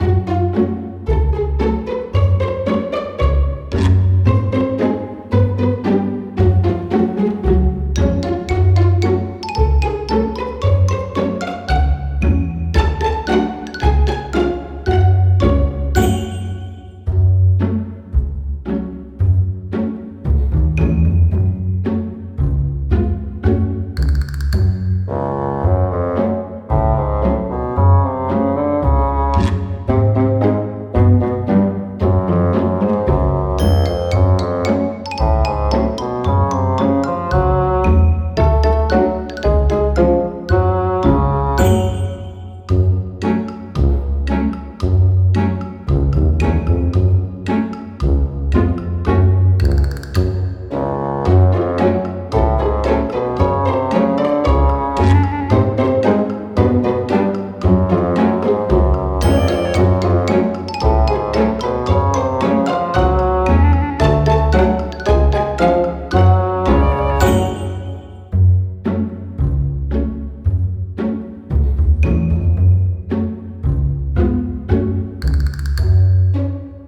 sneaky-mischief-loop.wav